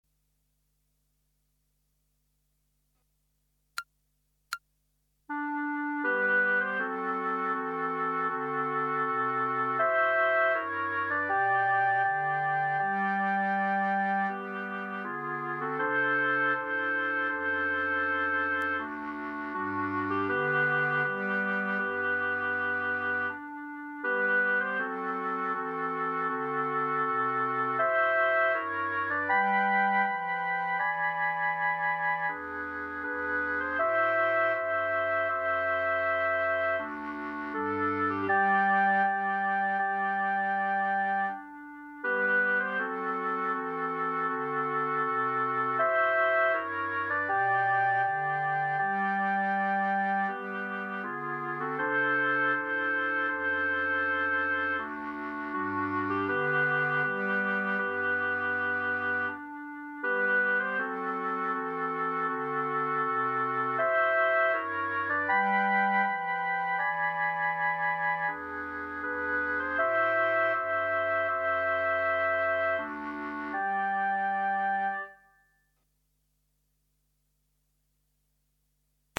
Clarinet Ensemble